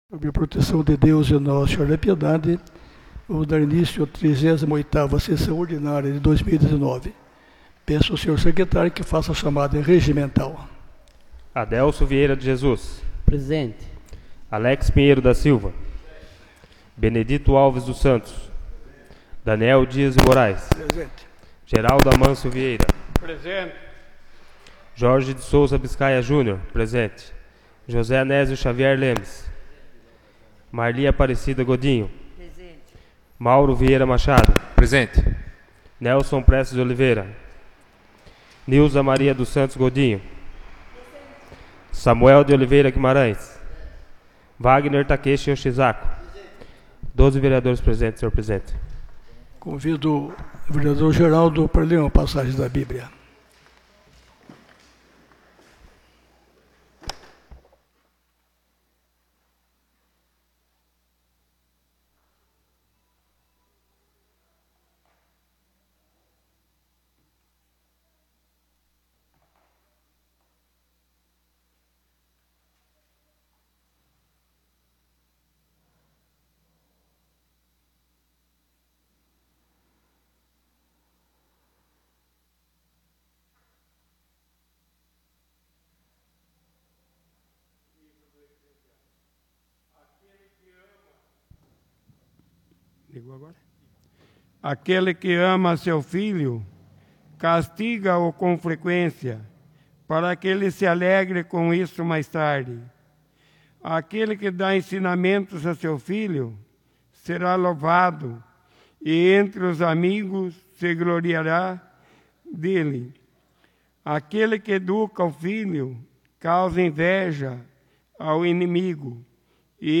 38ª Sessão Ordinária de 2019 — Câmara Municipal de Piedade